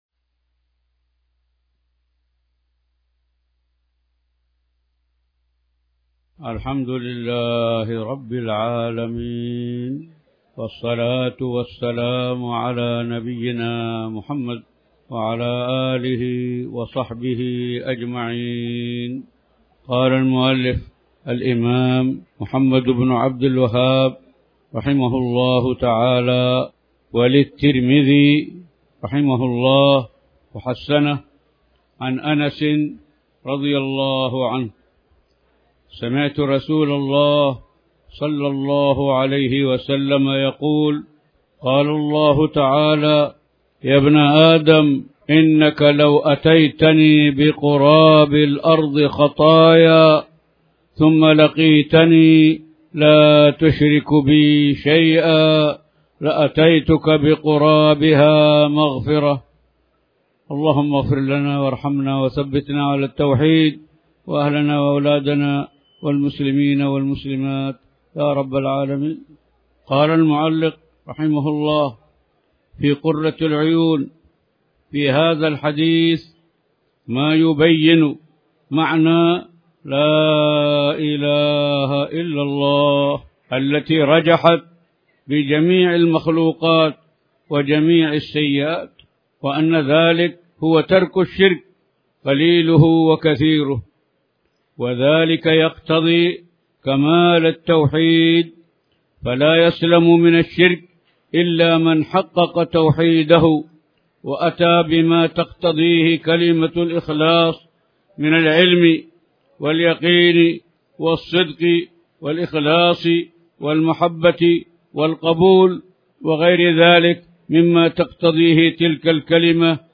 تاريخ النشر ٦ ذو الحجة ١٤٣٩ هـ المكان: المسجد الحرام الشيخ